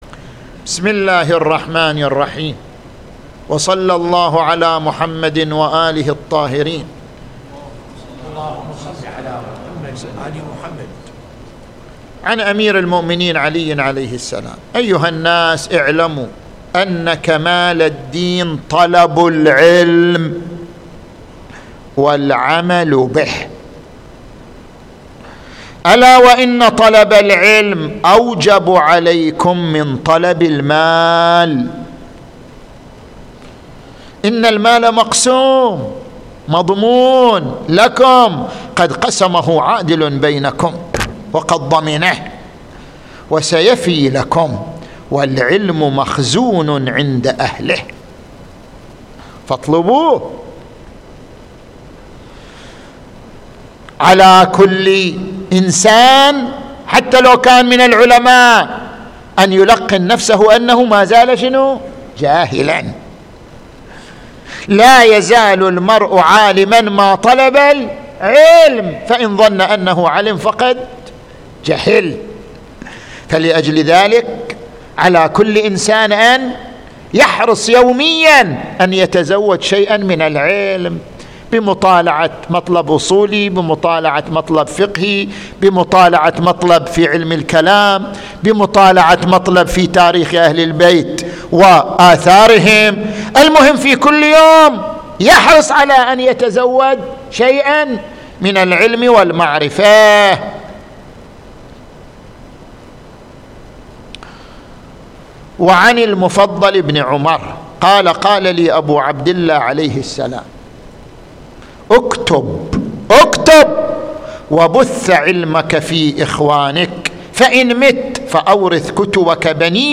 الموعظة الأسبوعية